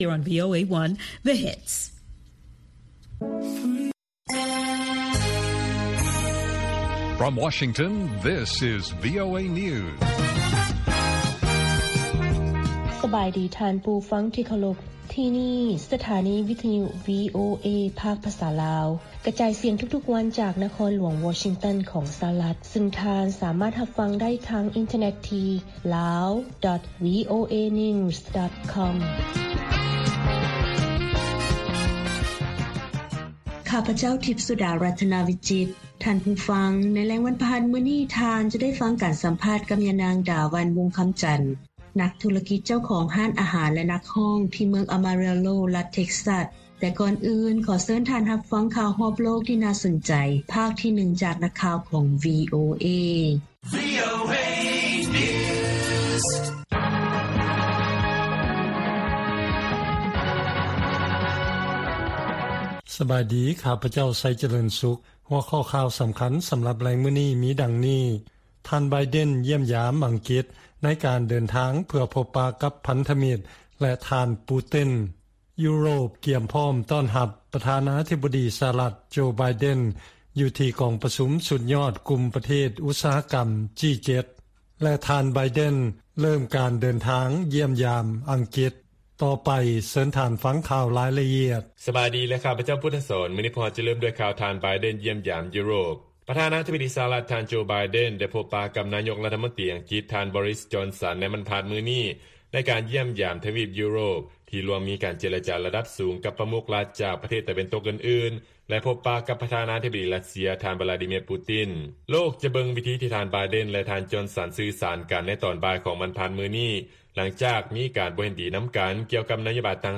ລາຍການກະຈາຍສຽງຂອງວີໂອເອ ລາວ: ຢູໂຣບ ກຽມຕ້ອນຮັບຢ່າງອົບອຸ່ນ ການເດີນທາງໄປຮ່ວມ ກອງປະຊຸມສຸດຍອດ ກຸ່ມ G-7 ຂອງ ປ. ໄບເດັນ ແຕ່ກໍຍັງມີຂໍ້ສົງໄສຢູ່
ວີໂອເອພາກພາສາລາວ ກະຈາຍສຽງທຸກໆວັນ. ຫົວຂໍ້ຂ່າວສໍາຄັນໃນມື້ນີ້ມີ: 1) ຢູໂຣບ ກຽມຕ້ອນຮັບຢ່າງອົບອຸ່ນ ການເດີນທາງໄປຮ່ວມ ກອງປະຊຸມສຸດຍອດ ກຸ່ມ G-7 ຂອງ ປ. ໄບເດັນ ແຕ່ກໍຍັງມີຂໍ້ສົງໄສຢູ່.